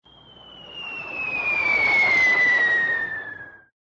incoming_whistleALT.ogg